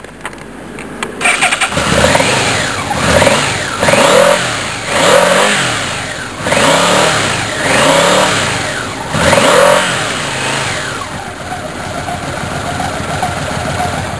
Vous allez trouver tous les sons de bécanes ici, ça va du bruit d'échappement quelconque aux moteurs de sportives en furie, je vous laisse découvrir...
V-Max 1200 Yamaha